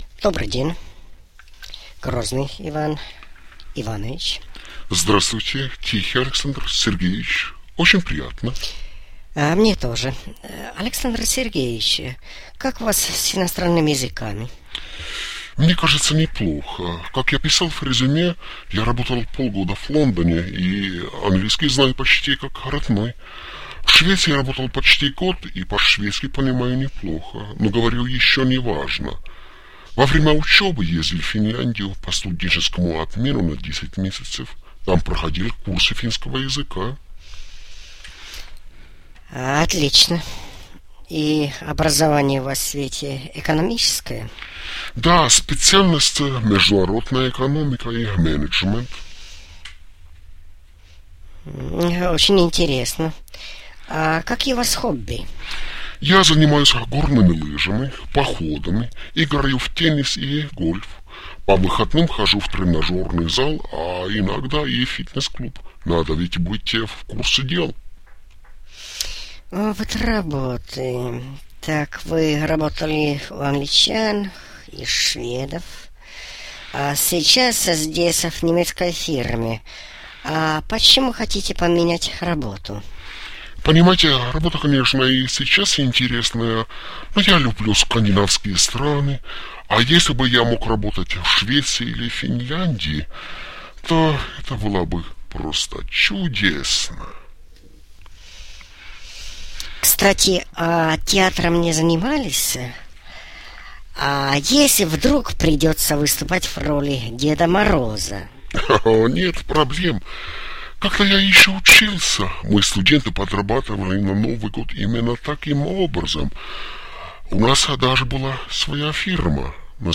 2. Интервью со вторым кандидатом.